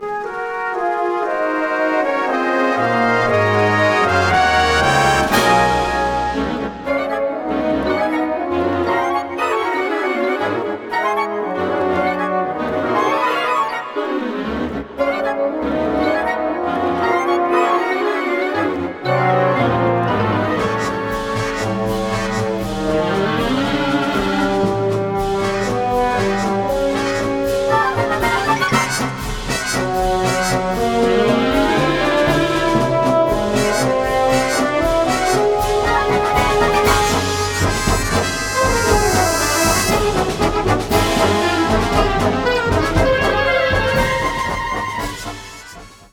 This new concert band studio recording